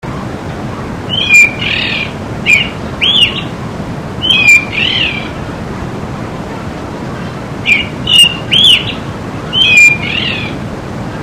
zorzal1.mp3